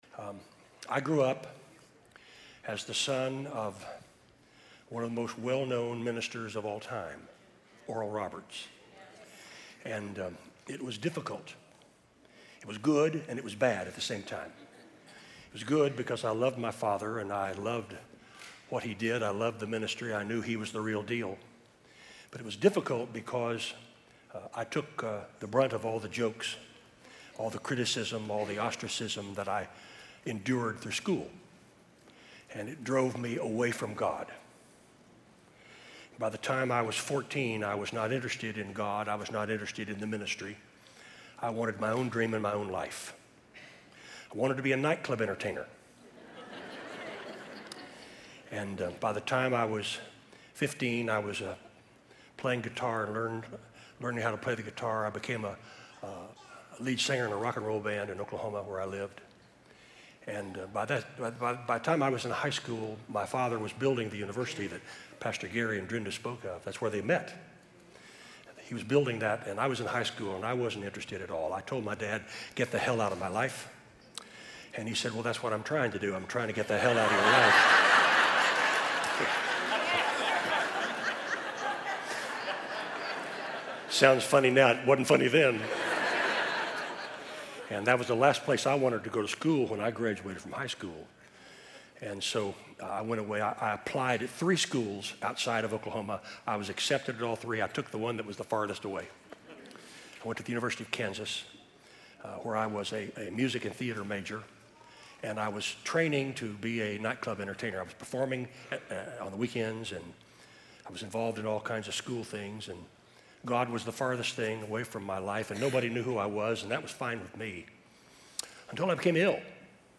Miracle Healing Service